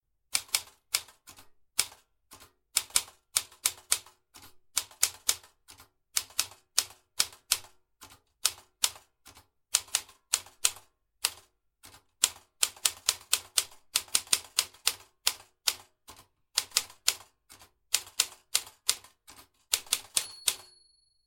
Звуки печатания
Звук клавиш старой пишущей машинки